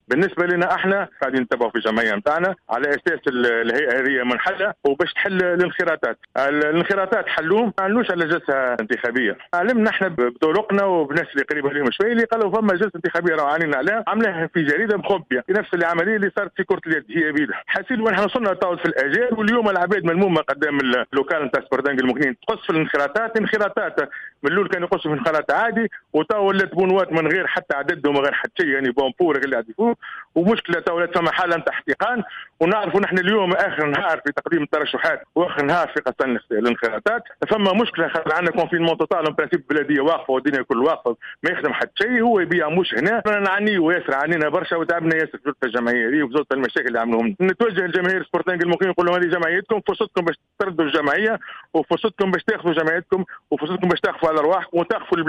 تصريح للجوهرة اف ام